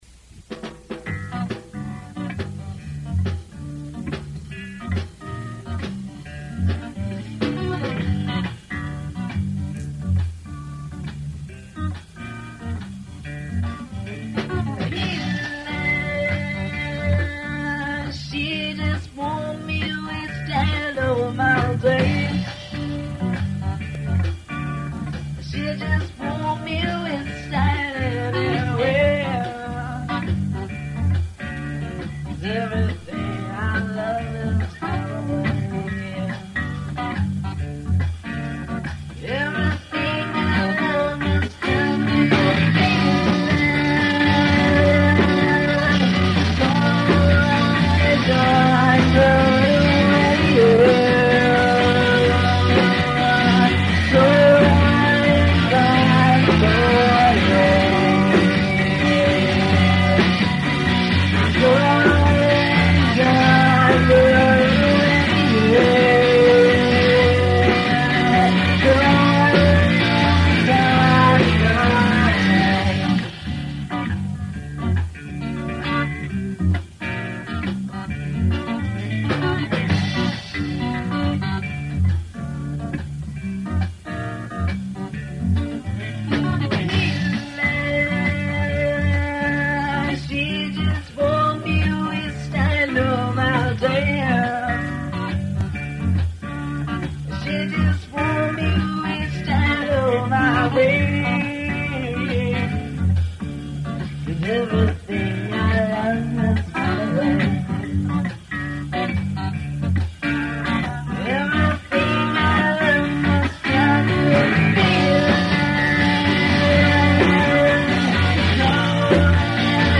DEMO RECORDINGS